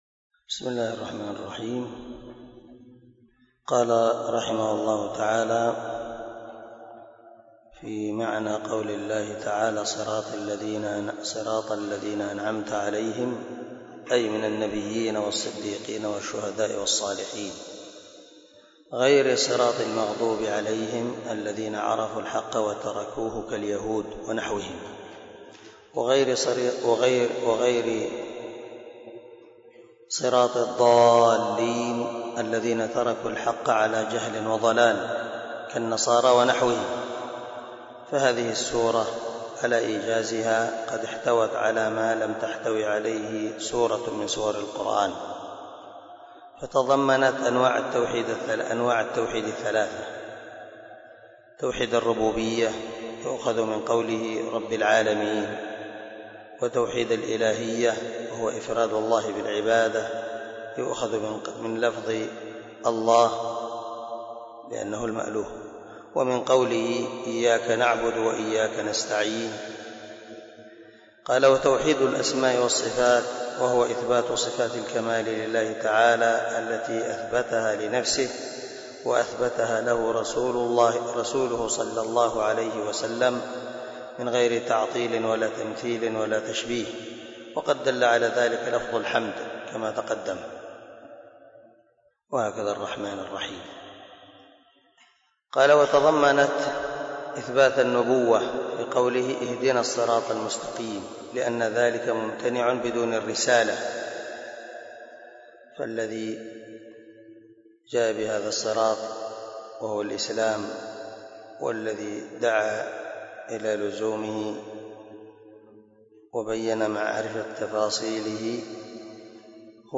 011الدرس 1 تفسير آية ( 1 - 5 ) من سورة البقرة من تفسير القران الكريم مع قراءة لتفسير السعدي
دار الحديث- المَحاوِلة- الصبيح